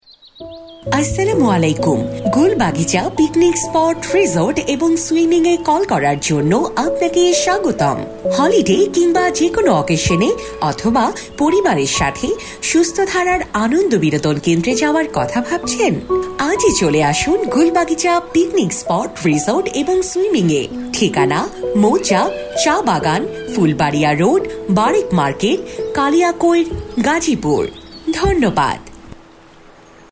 1.-gulbagicha-piknik-spot-caller-tune-voice-5.mp3